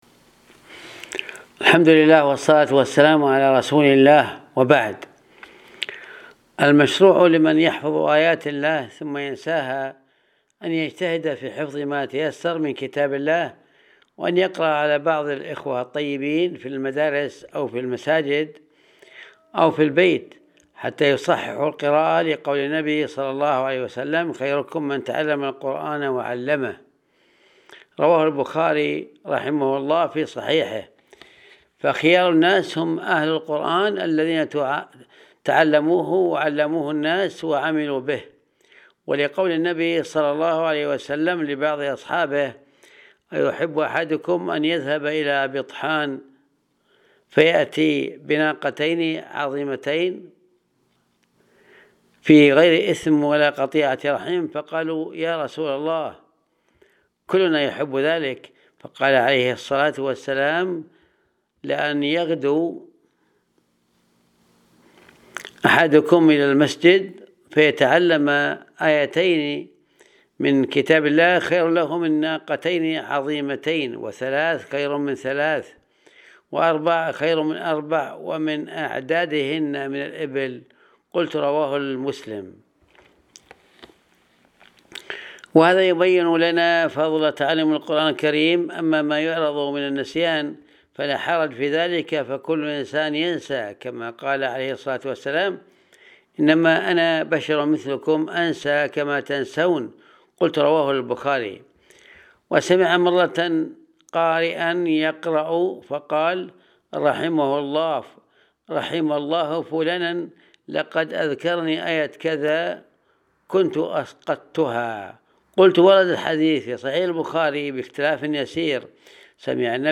المحاضرة